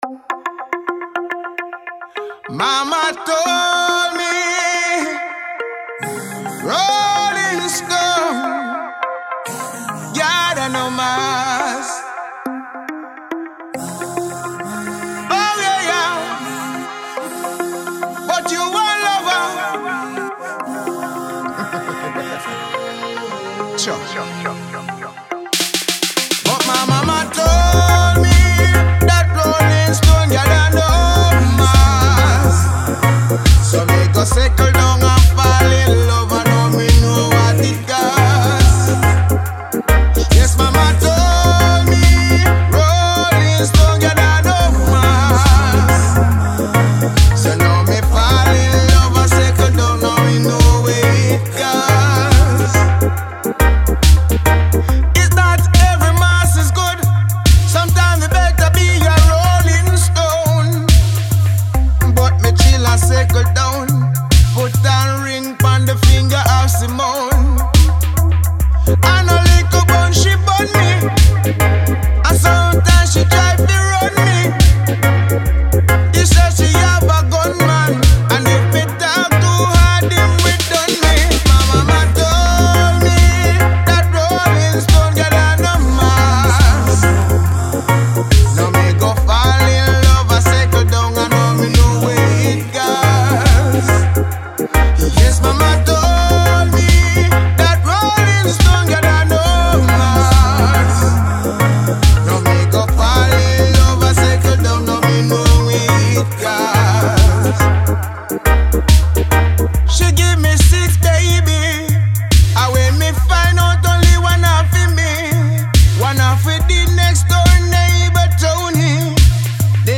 Reggae Magazine